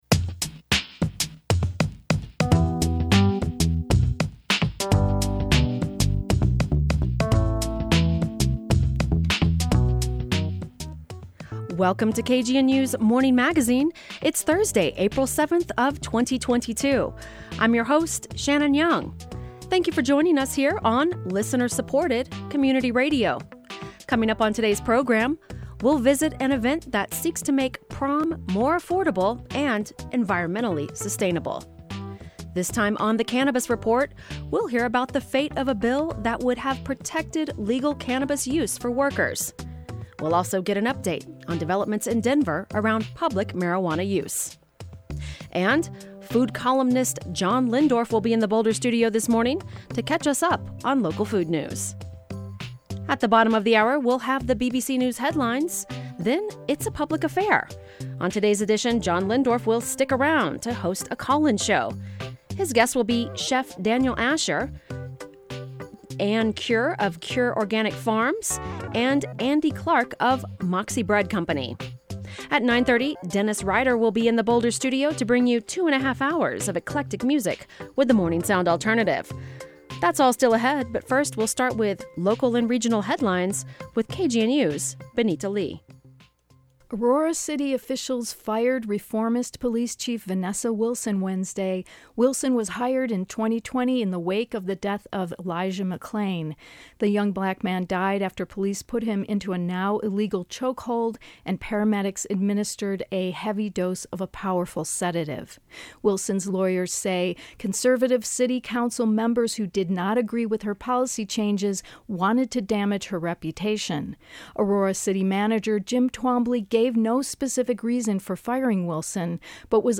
The Morning Magazine features local news headlines, stories, and features and broadcasts on KGNU Monday through Friday 8.04-8.30 AM.